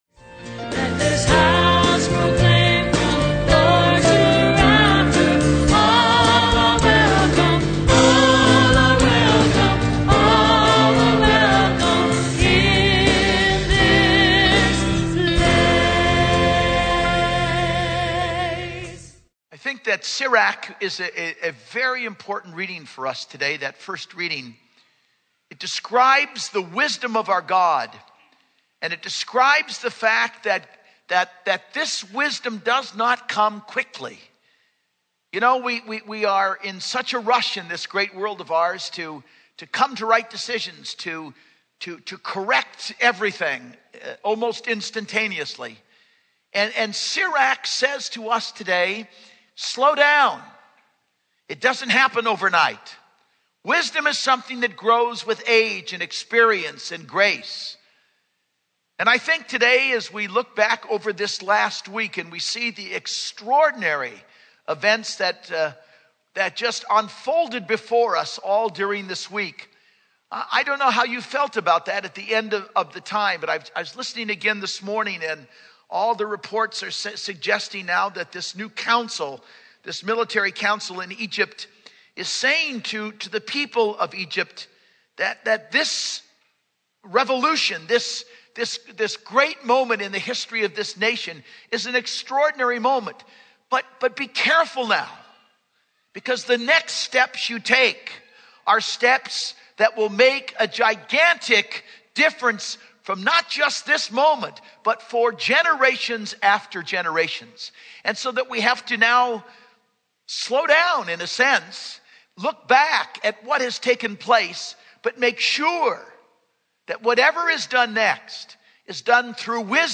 Homily - 2/13/11 - 6th Sunday Ordinary Time